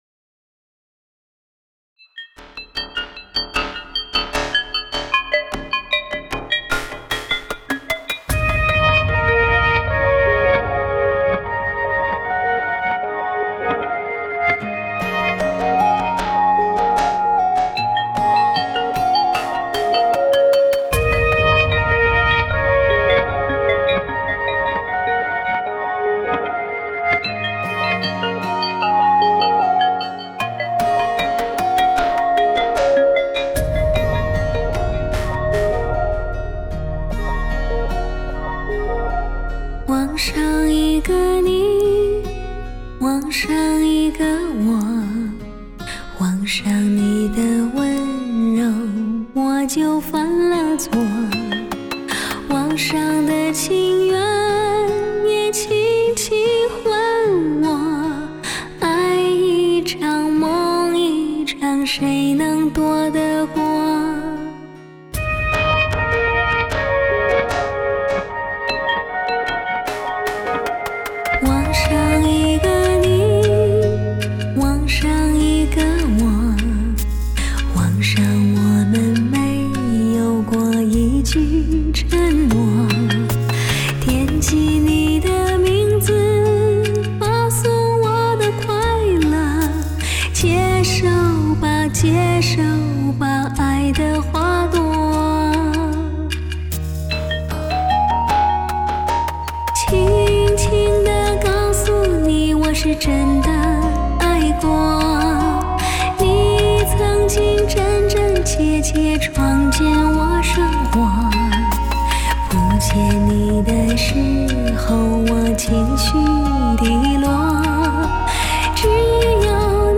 丝丝入扣的细腻音质华丽绽放 迭起的绝美颤音引人浮想
柔美细腻的女音 凭借领先国际的DTS
多声道环绕和多重混响技术
甜美的中音 以及醇厚的低音等
人声的三频自然而真实 更甚的是连口水声也尽录